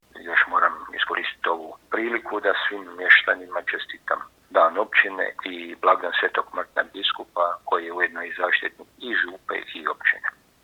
Načelnik Ivoš ovom prigodom uputio je i čestitku svim mještanima Općine Martinska Ves